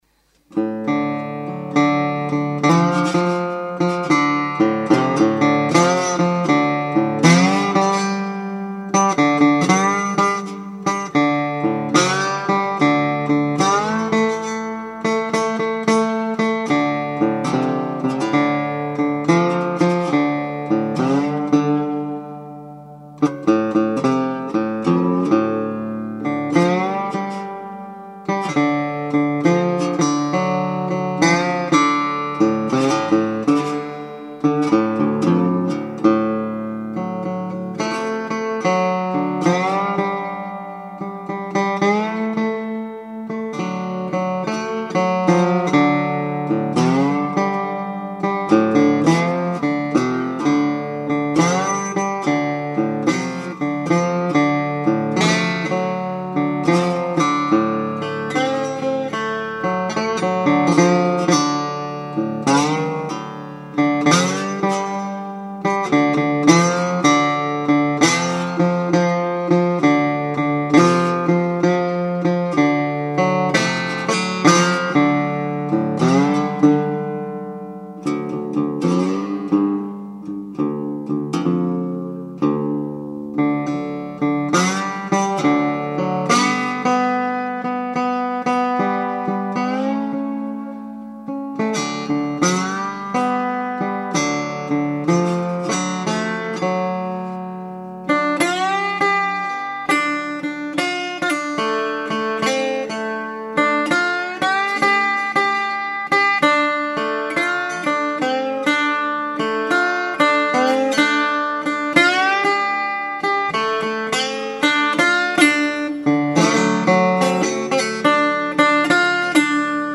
16:40 Bonjour non je ne connais pas par contre j'ai un tricone Johnson et j'en suis trés content
Resonator&steelbar.mp3